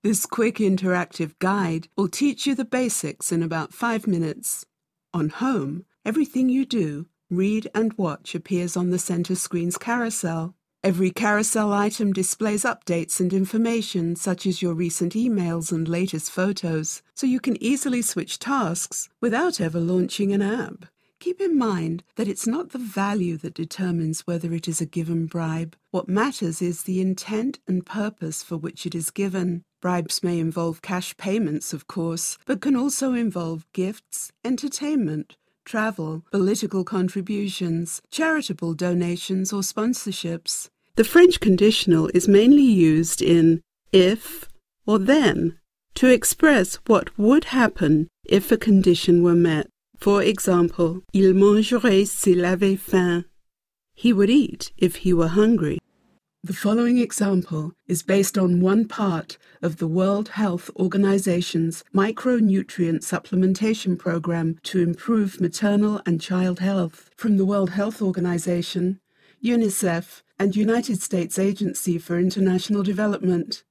Voiceover actor working in British and Global Mid-Atlantic English from Los Angeles
Sprechprobe: eLearning (Muttersprache):
Warm, sophisticated and believable. Alto timbre.